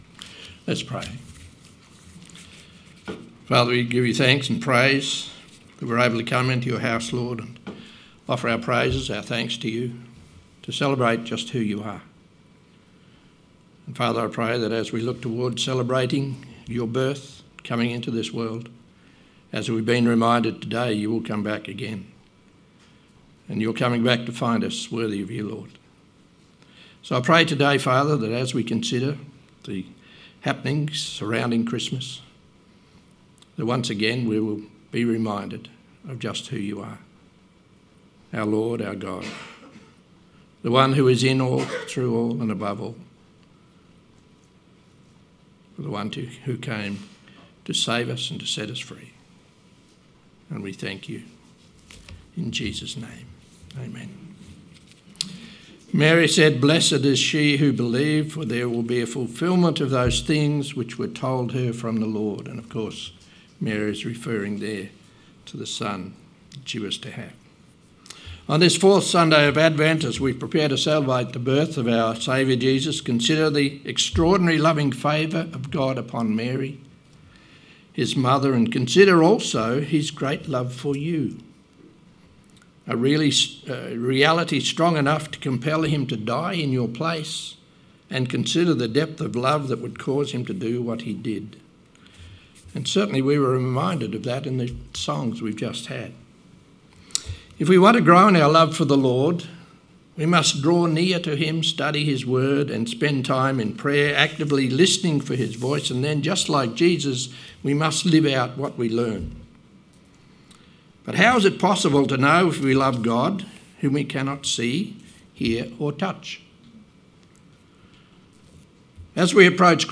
Sermon 22nd December 2024 – A Lighthouse to the community